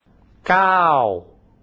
The sound in the sound file will sound like a "g" to you because your mind has already built its compartments for the sounds that there are, and the closest equivalent in your mind's own dictionary is a 'g'.
Sounds like a g to me